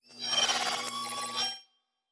pgs/Assets/Audio/Sci-Fi Sounds/Electric/Data Calculating 5_5.wav at master
Data Calculating 5_5.wav